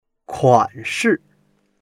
kuan3shi4.mp3